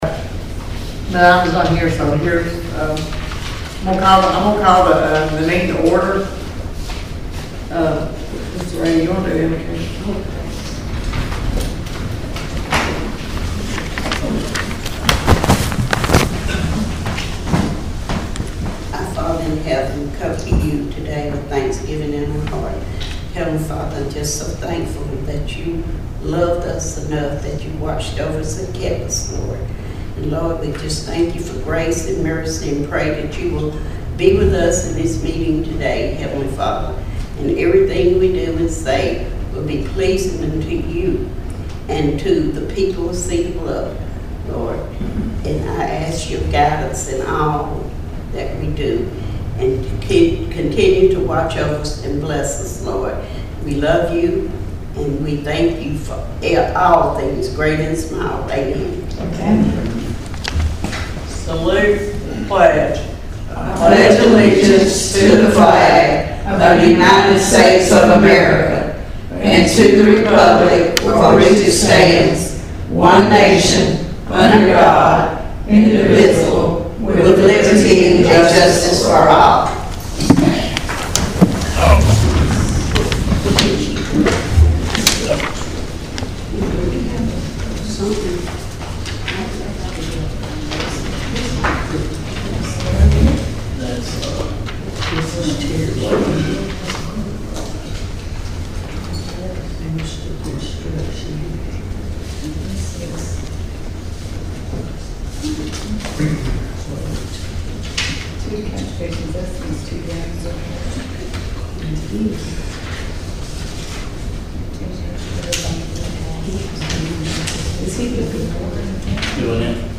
Cedar Bluff Town Council Special Called Meeting - WEIS | Local & Area News, Sports, & Weather